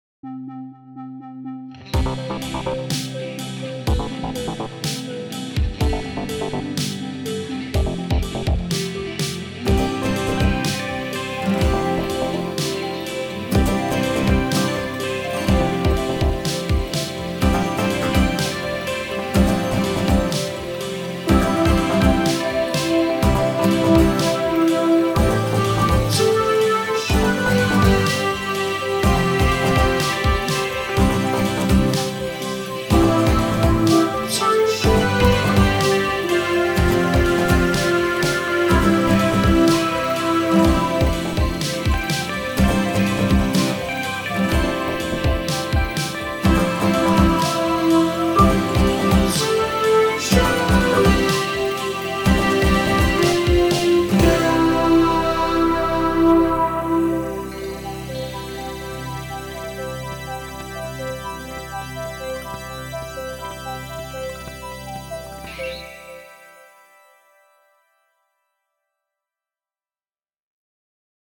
The show is a queer Q&A meant to delve into the roots of our guest’s queerness – that moment they had an inkling that maybe the mainstream wasn’t meant for them.